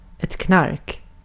Uttal
Synonymer narkotika drog Uttal Okänd accent: IPA: /knark/ Ordet hittades på dessa språk: svenska Ingen översättning hittades i den valda målspråket.